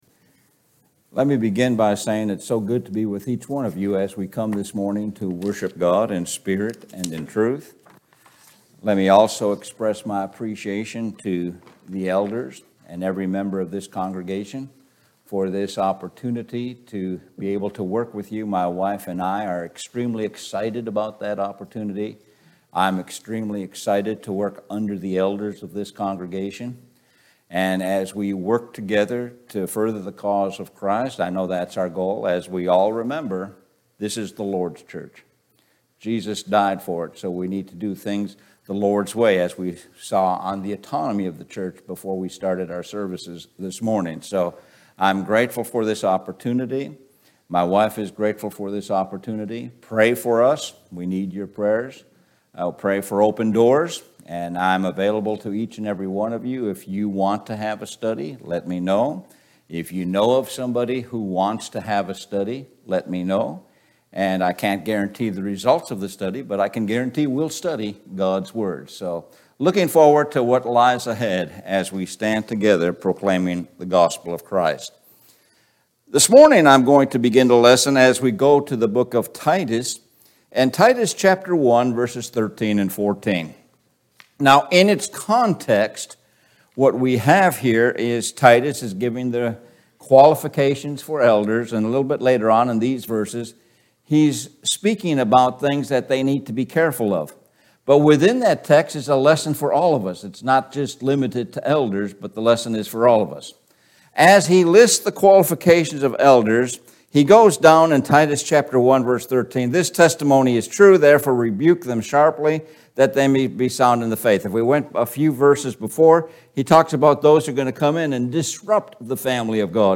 Sun AM Sermon 11.27.22